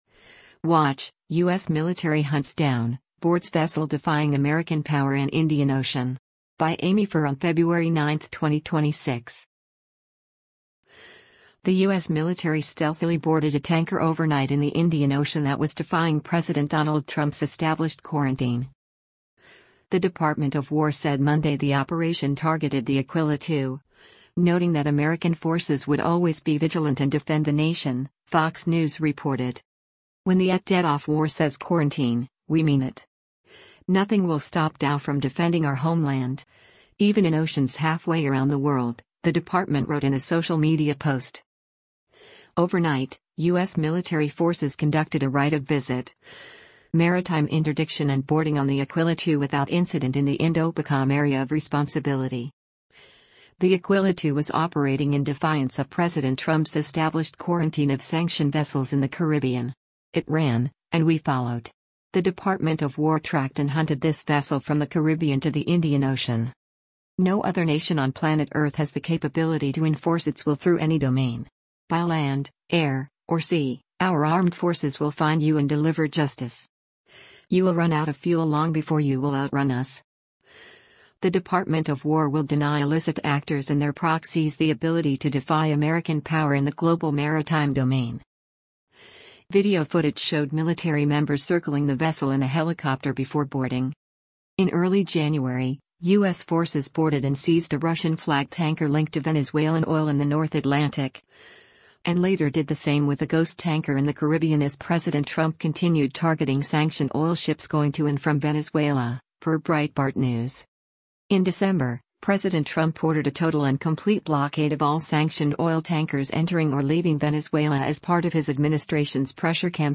Video footage showed military members circling the vessel in a helicopter before boarding: